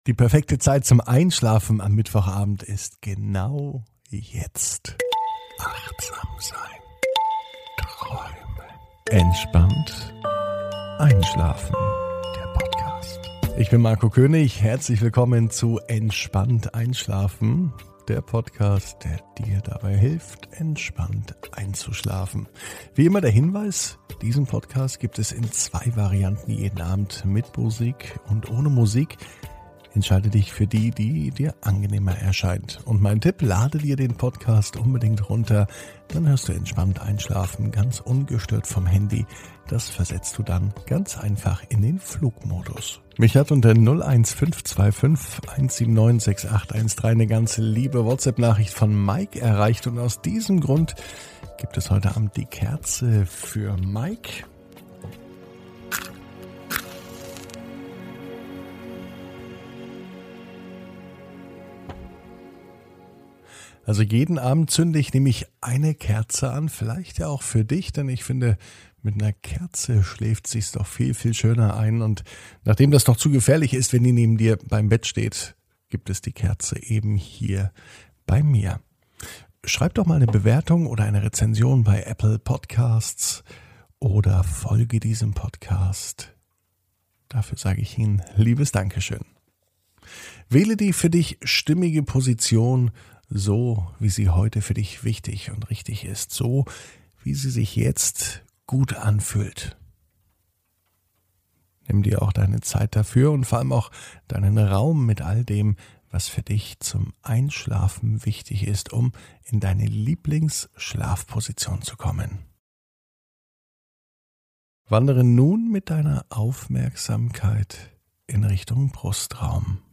(ohne Musik) Entspannt einschlafen am Mittwoch, 09.06.21 ~ Entspannt einschlafen - Meditation & Achtsamkeit für die Nacht Podcast